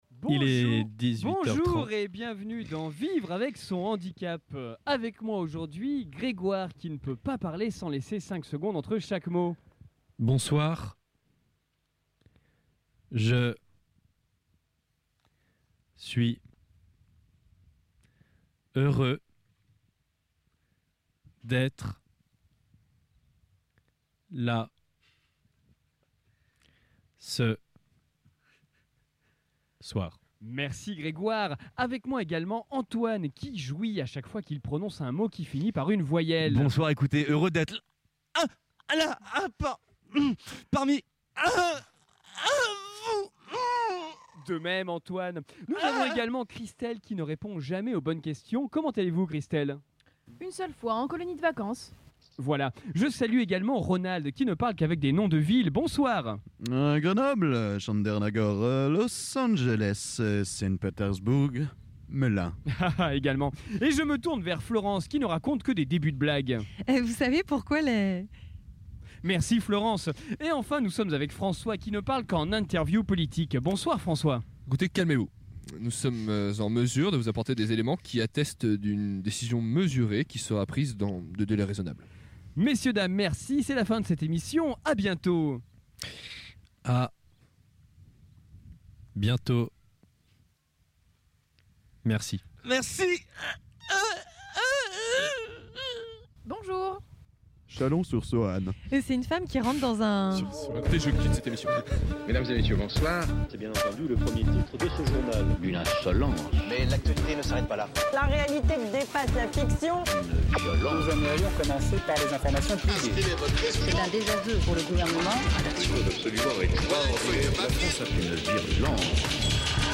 Un camion spécialement affrété pour l'occasion. 300 techniciens à pied d'oeuvre pour que le direct ne merde pas.